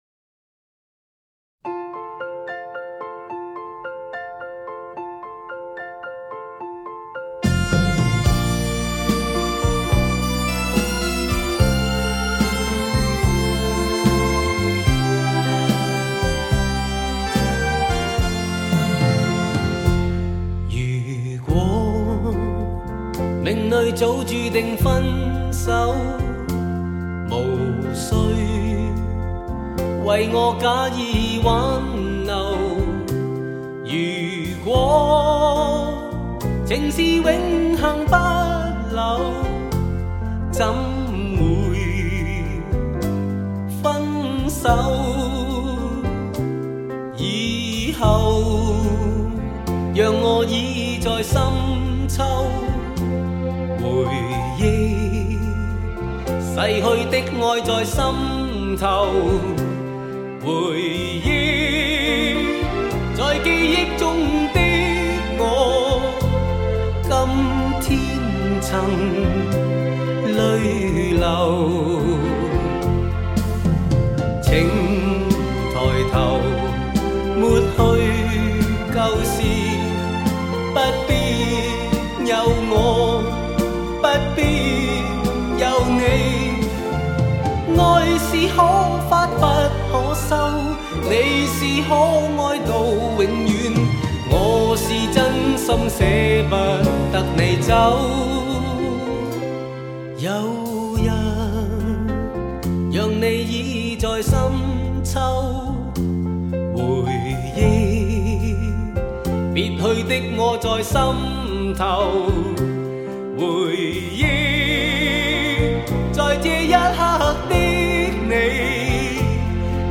香港DSD版
以普通CD价钱 享受16首SACD音效经典歌曲